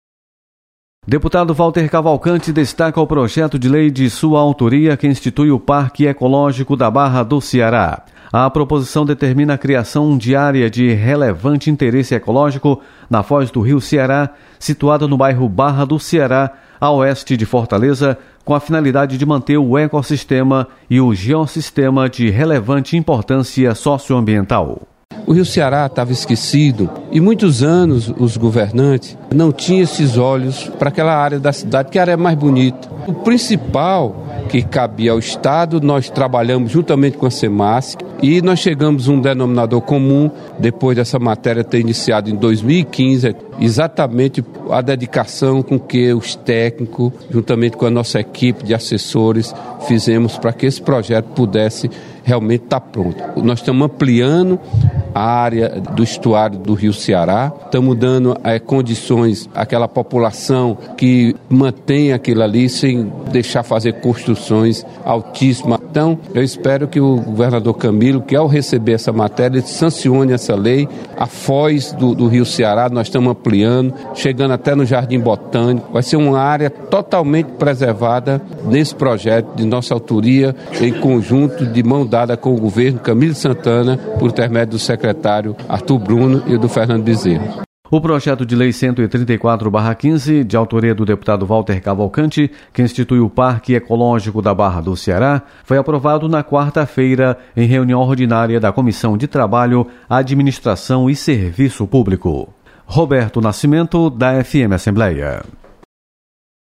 Deputado Walter Cavalcante destaca projeto que institui o Parque Ecológico do Ceará.